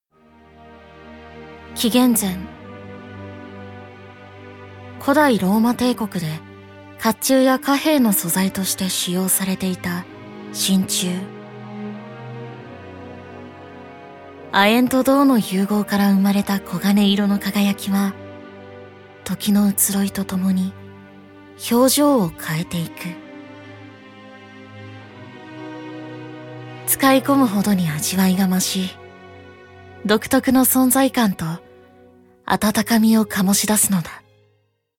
ボイスサンプル
(シックで品のある雰囲気)